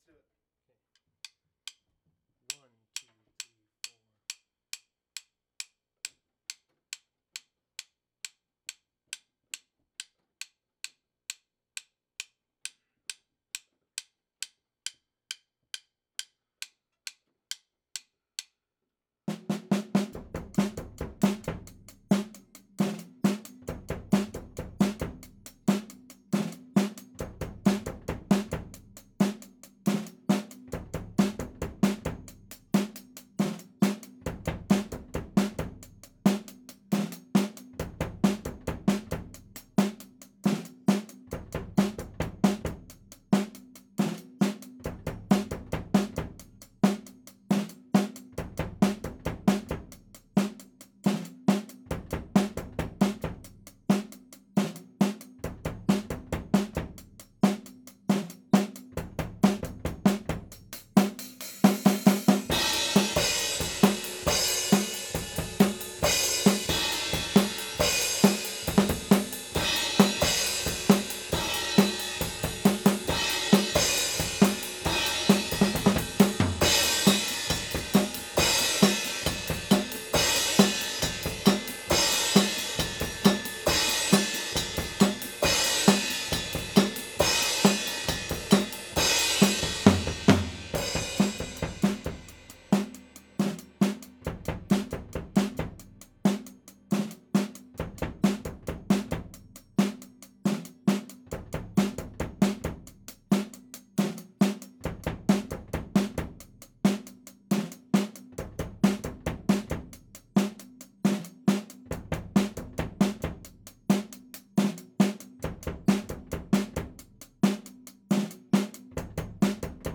Overheads (2).wav